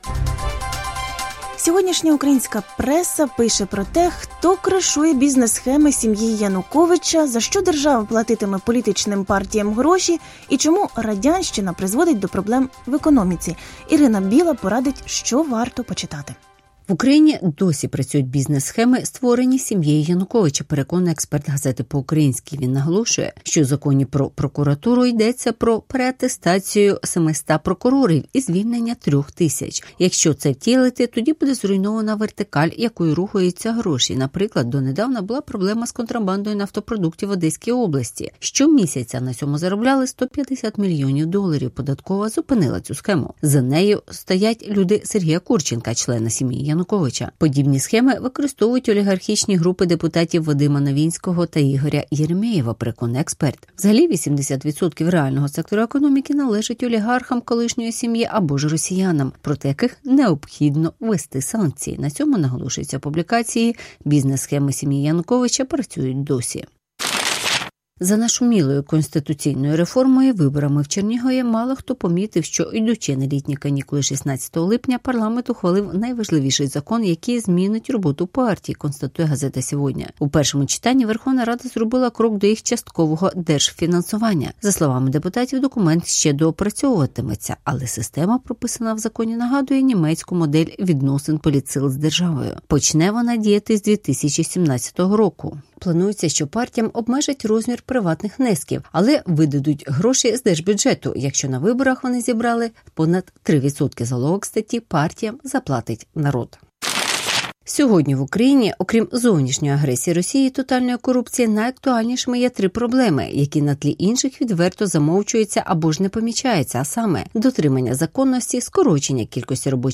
Бізнес-схеми «сім’ї» Януковича прикривають у вищих кабінетах? (Огляд преси)